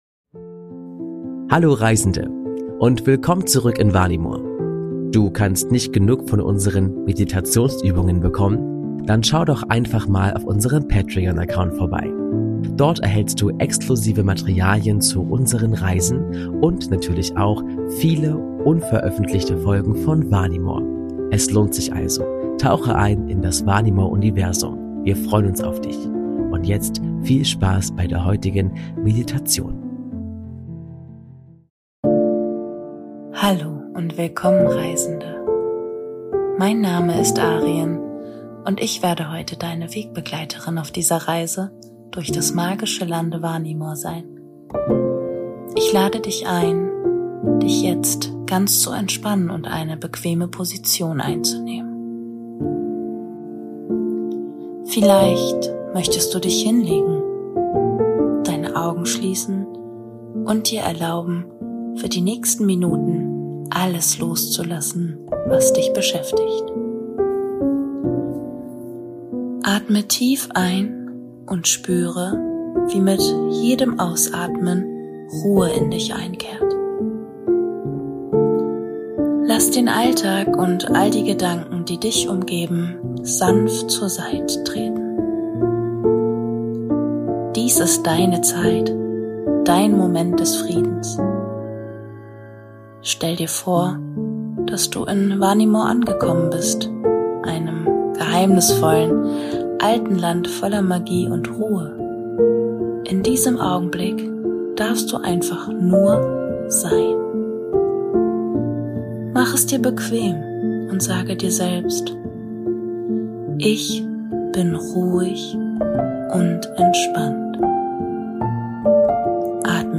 Entspannungsgeschichte: Der verwunschene Wald